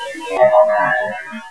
Electronic Voice Phenomena
The following files are from that afternoon of recording.
The next EVP leaves us wondering what this clear speaking ghost - spirit is talking about.